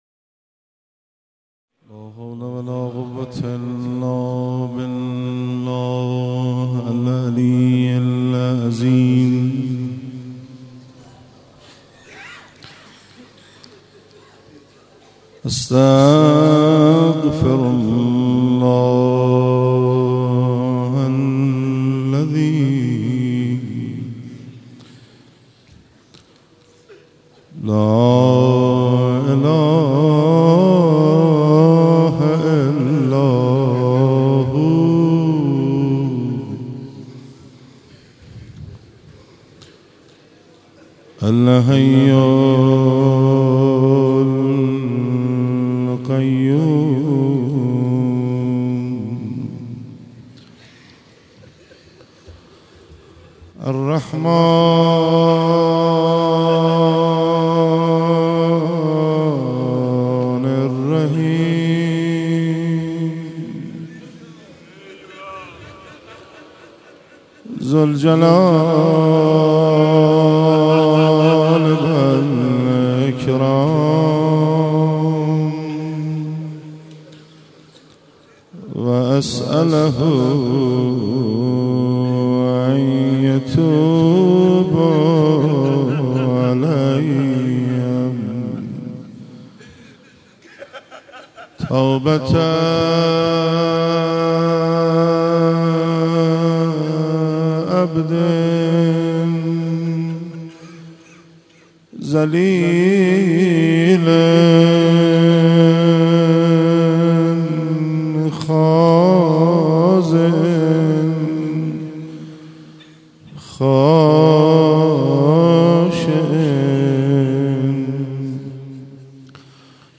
ذکر استغفار
روضه و ذکر